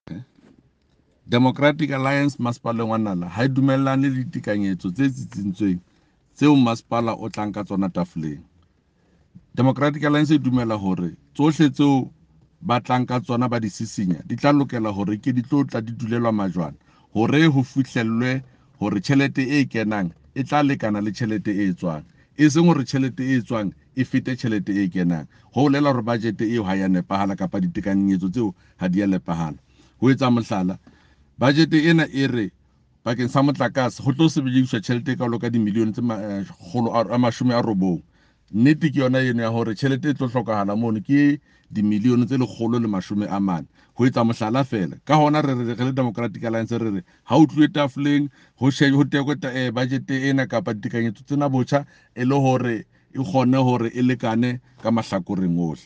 Note to editors: Please find attached English and Afrikaans soundbites by Cllr David Ross and
Sesotho by Jafta Mokoena MPL.